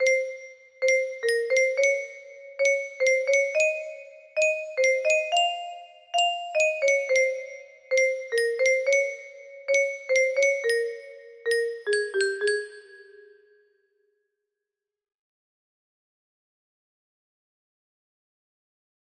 la la la la la la la la la music box melody
Full range 60